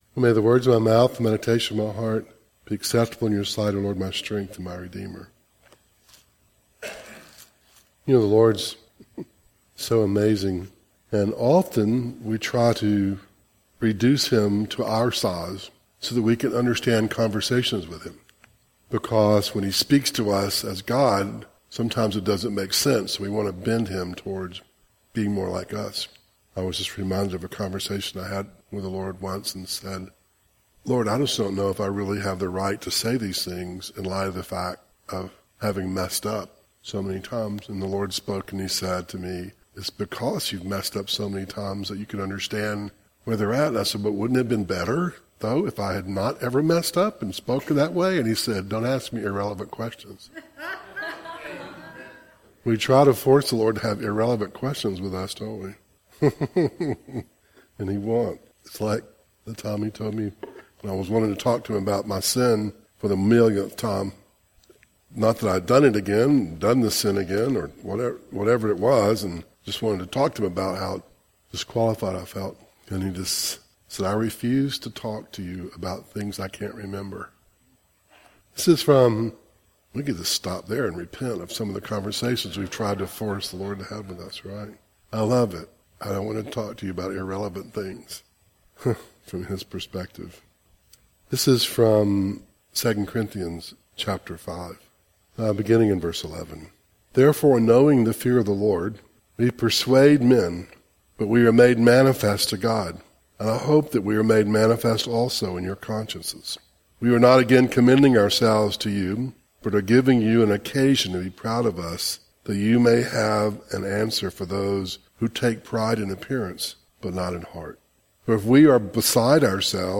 Service Type: Devotional